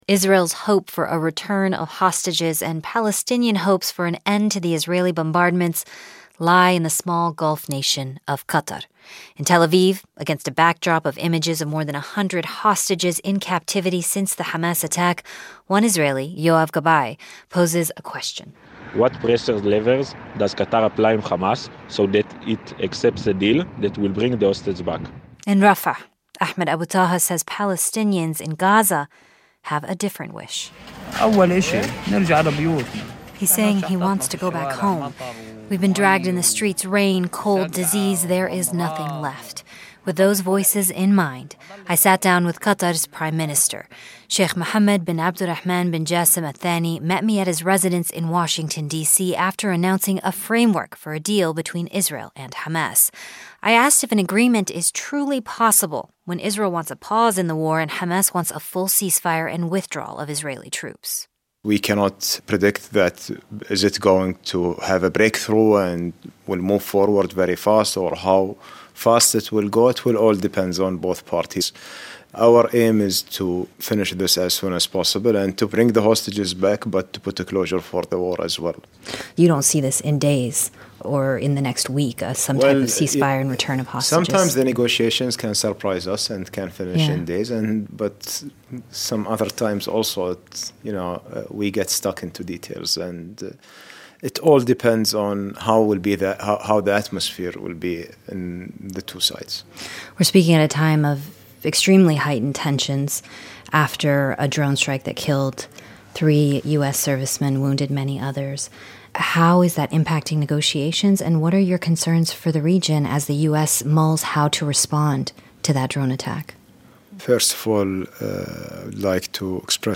NPR's Leila Fadel talks to Qatar's Prime Minister Sheikh Mohammed bin Abdulrahman bin Jassim Al Thani about brokering a cease-fire in the Israel-Hamas war, and an exchange of hostages and prisoners.